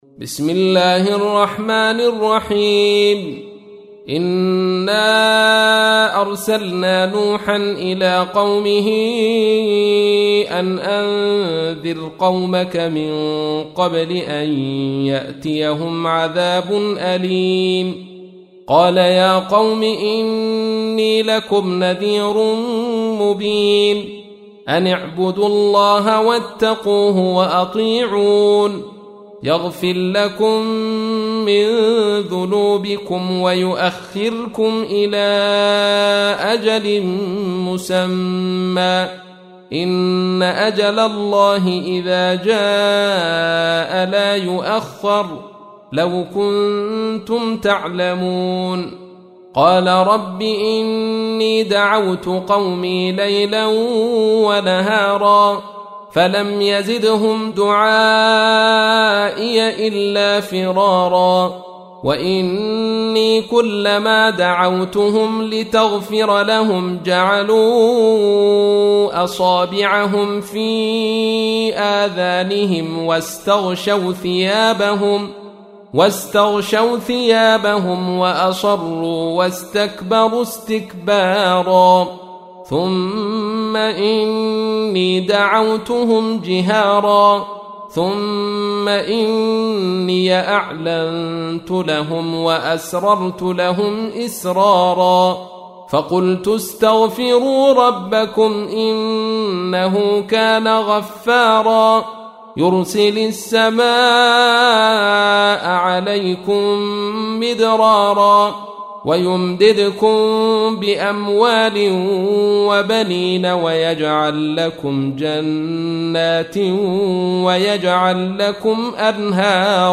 تحميل : 71. سورة نوح / القارئ عبد الرشيد صوفي / القرآن الكريم / موقع يا حسين